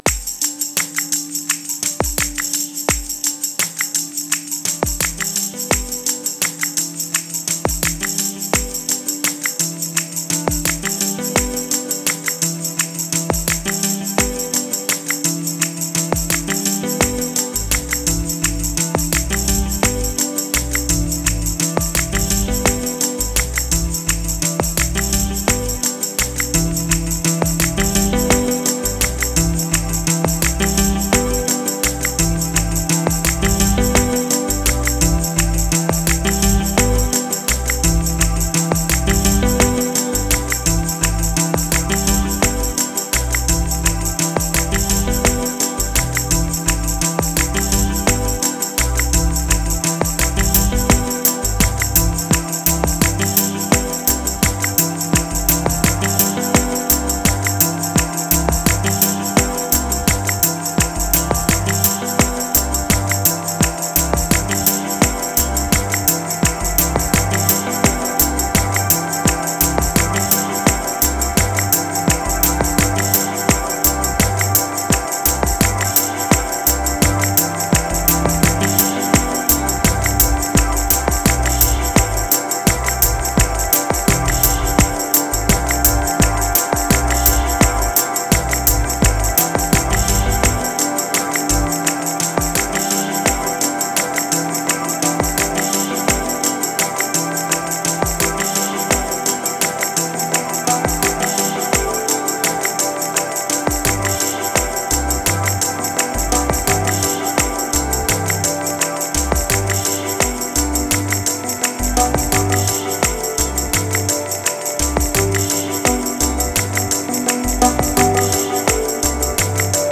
Moods Demo Single Memories Afar Abyss Clone Resume Ambient